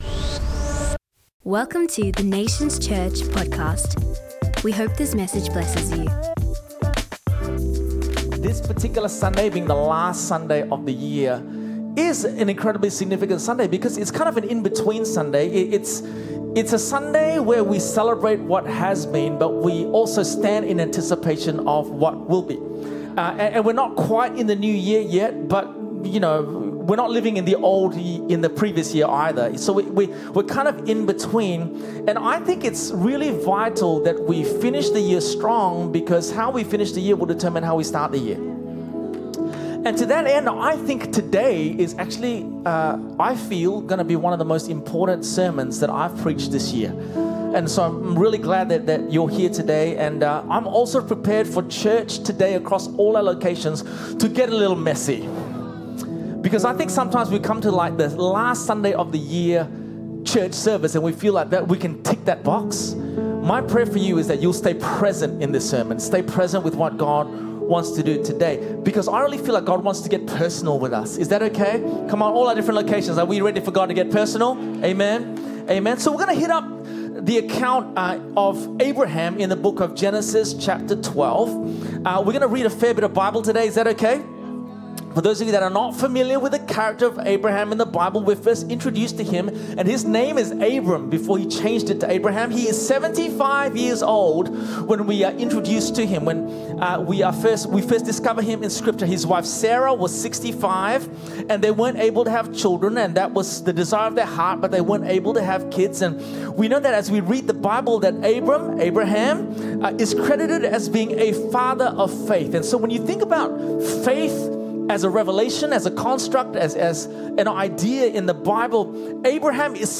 This podcast hosts every Sundays message that is streamed out of our Myaree Campus in Perth, Western Australia.